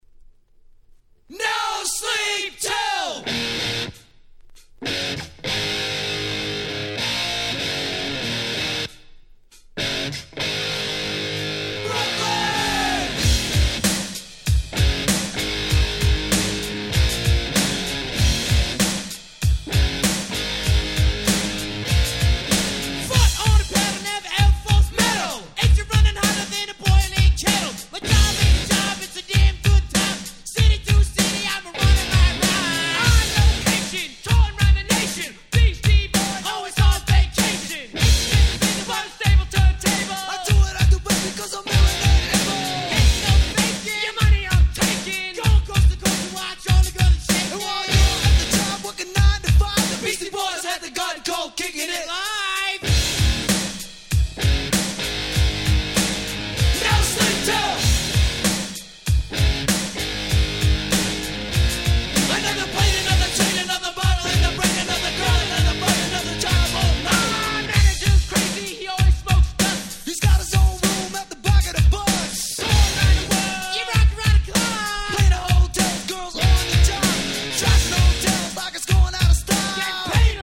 Rock調の楽曲あり、ClassicなOld Schoolありの飽きの来ない最強の名盤！！